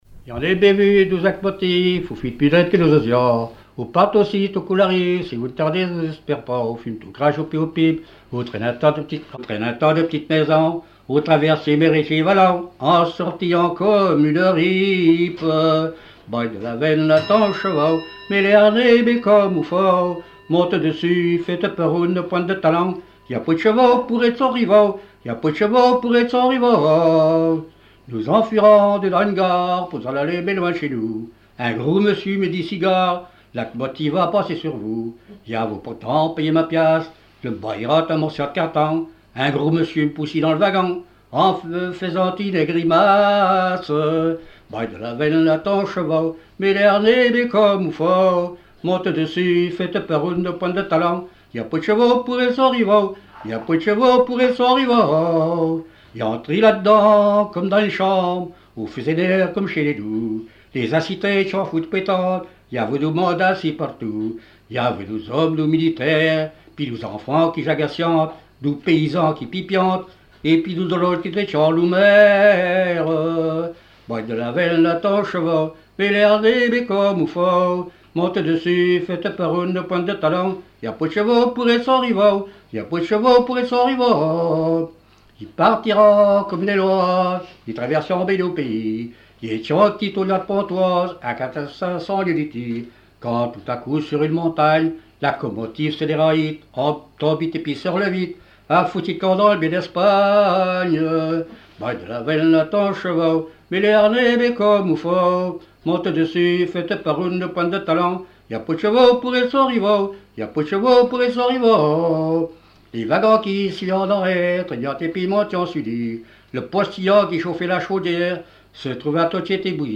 Localisation Noirmoutier-en-l'Île (Plus d'informations sur Wikipedia)
Catégorie Pièce musicale inédite